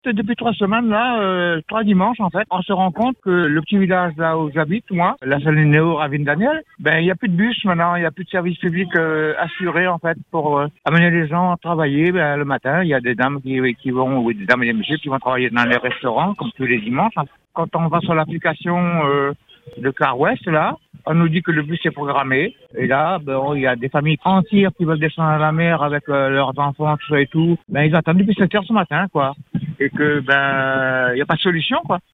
Un riverain témoigne :